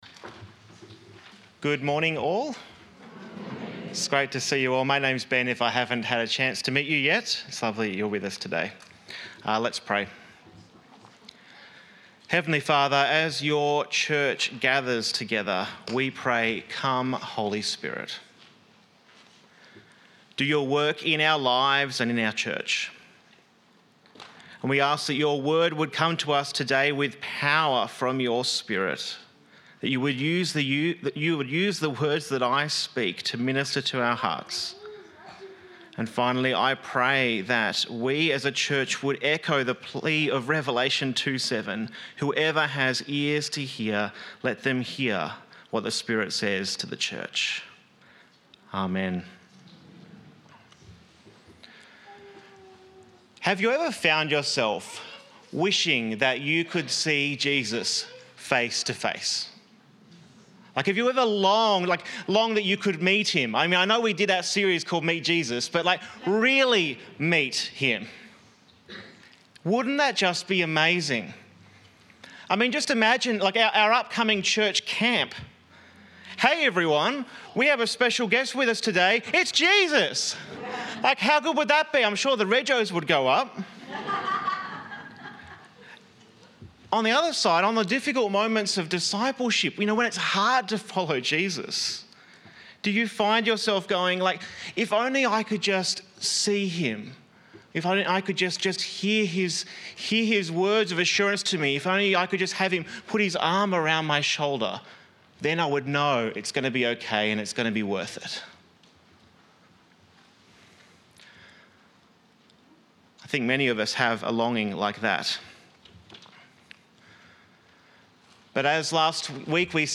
Life in the Spirit Current Sermon The Spirit and Jesus Life in the Spirit John 14:15-26